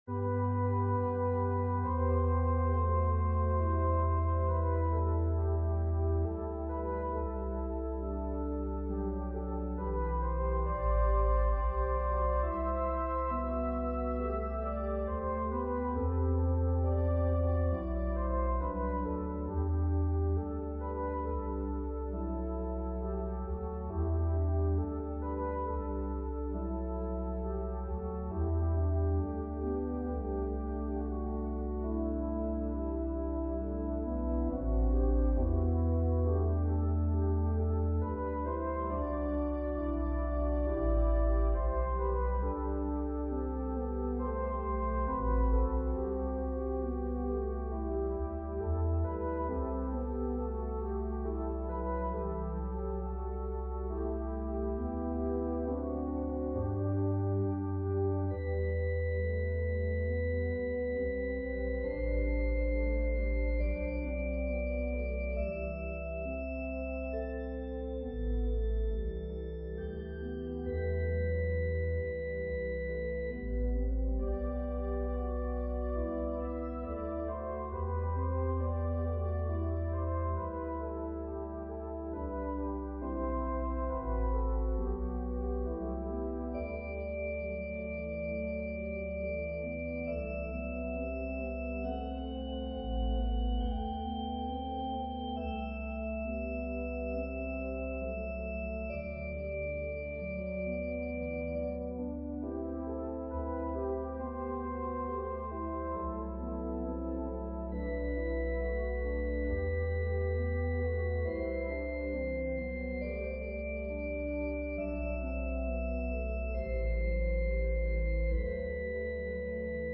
and arranged it for organ solo.
Voicing/Instrumentation: Organ/Organ Accompaniment